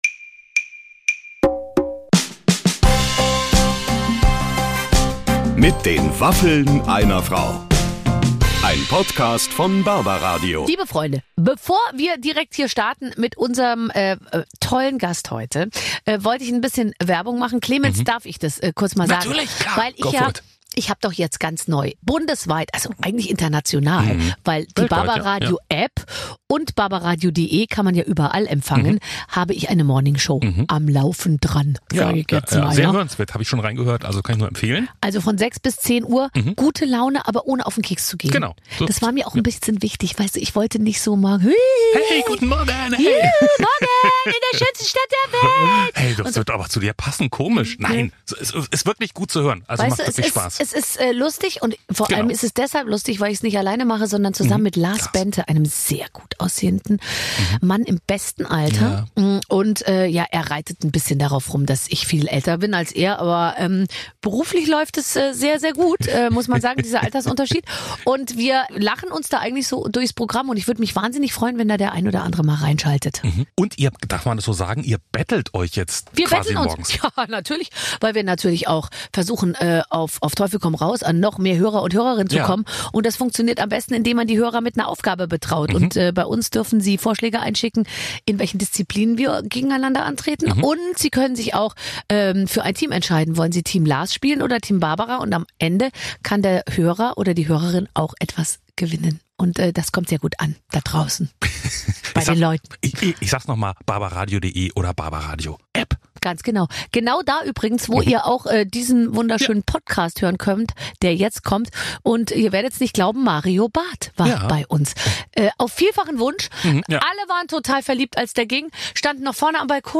Jedes Wochenende trifft Barbara Schöneberger Prominente aus Musik, Fernsehen, Sport und Showbiz. Der Unterschied zu allen anderen Talkshows: Barbara Schöneberger hat Waffeln gebacken.